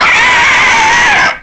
File:Dogadon's Monster Bird Screech.oga
Dogadon sound effect from Donkey Kong 64
Dogadon's_Monster_Bird_Screech.oga.mp3